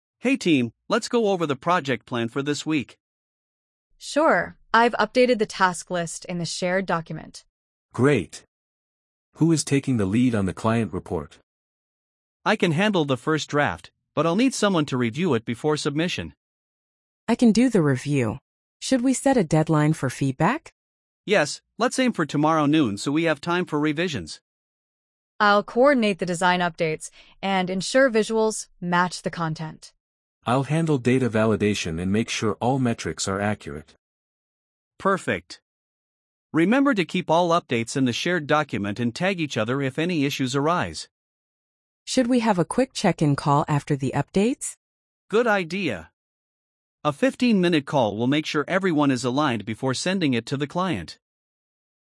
🤝 A team plans their weekly tasks and responsibilities.